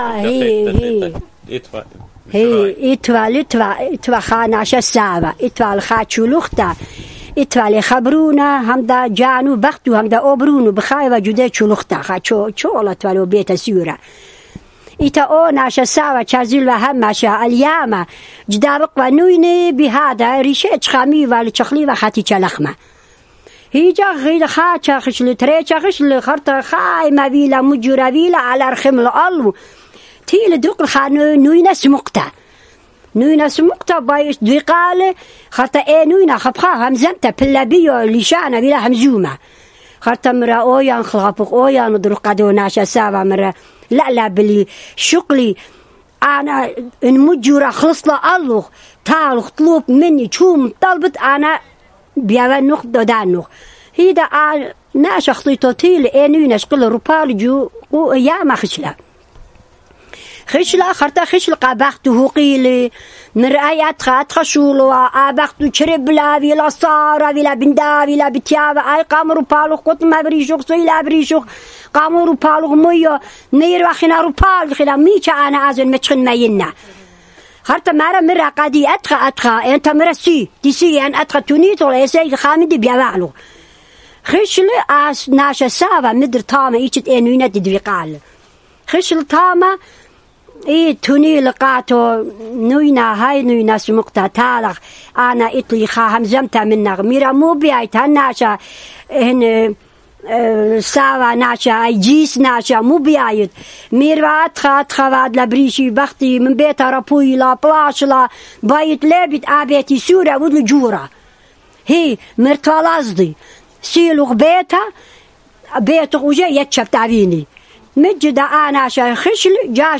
Urmi, Christian: The Old Man and the Fish